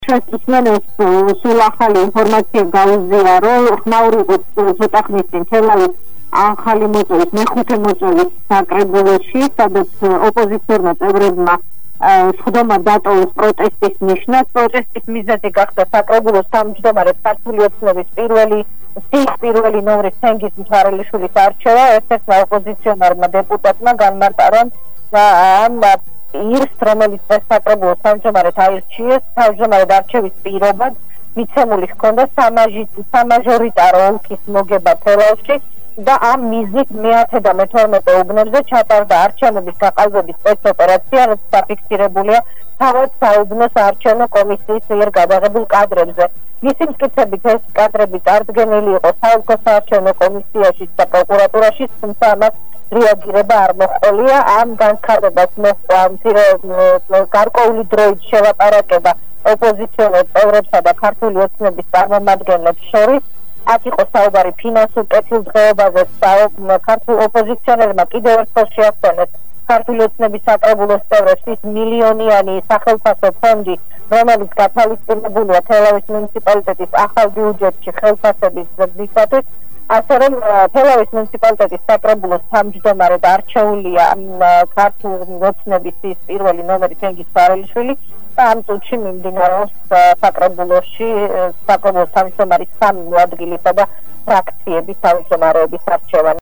ხმაური იყო თელავის მუნიციპალიტეტის მეხუთე მოწვევის საკრებულოში, სადაც ერთიანი ნაციონალური მოძრაობის წევრებმა, წარმომადგენლობითი ორგანოს ახალი თავმჯდომარის არჩევის გამო, დარბაზი დატოვეს.